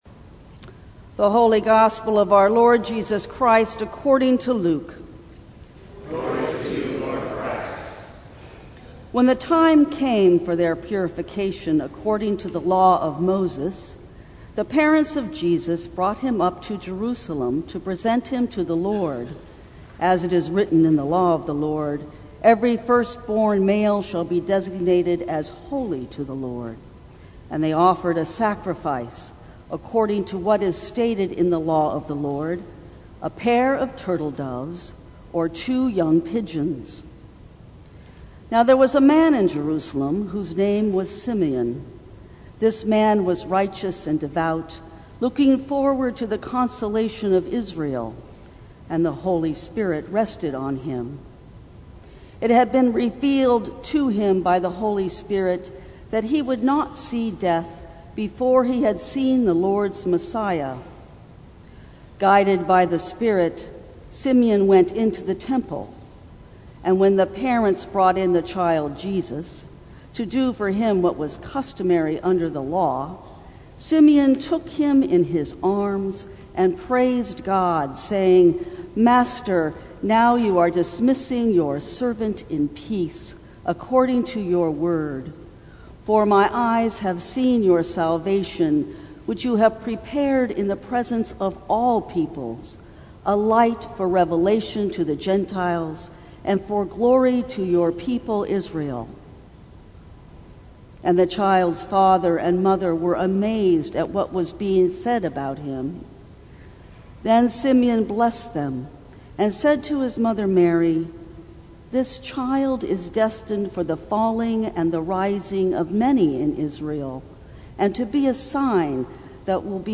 Sermons from St. Cross Episcopal Church Confidence Feb 16 2020 | 00:14:46 1x 00:00 / 00:14:46 Subscribe Share Apple Podcasts Spotify Overcast RSS Feed Share Link Embed